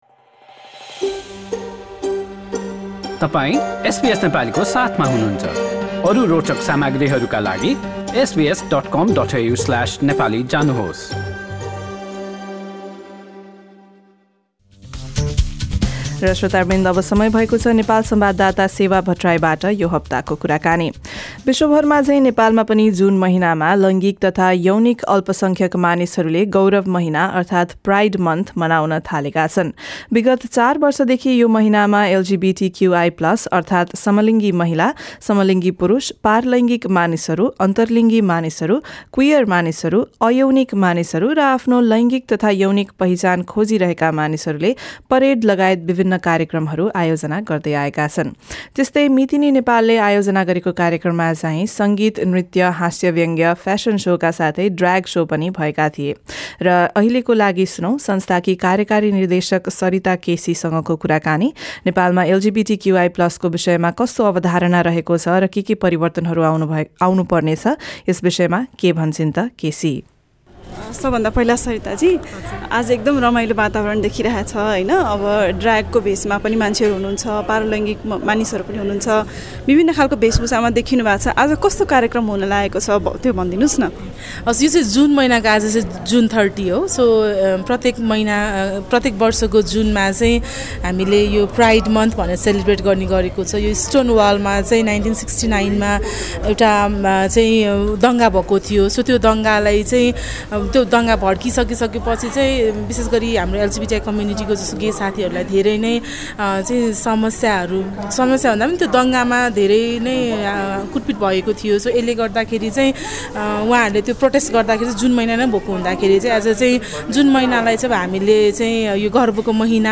गरेको कुराकानी।